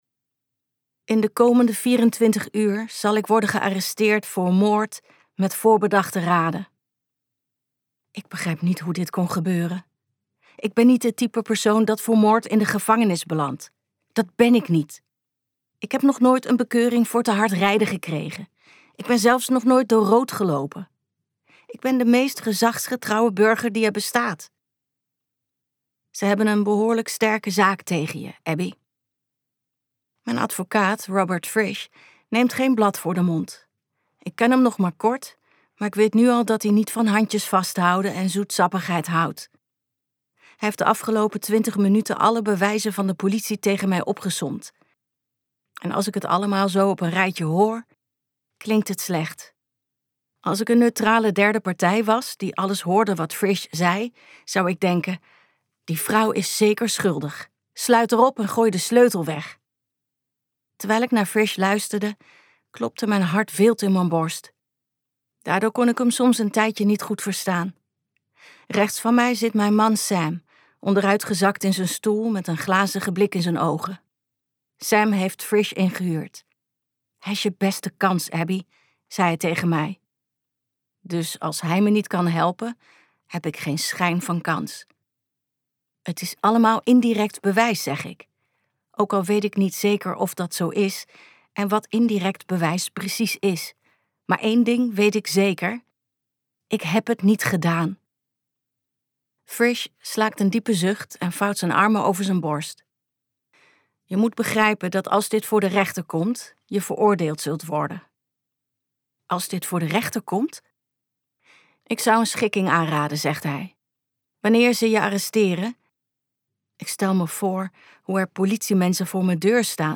Luister het gratis fragment De draagmoeder Ze gaf je een leven.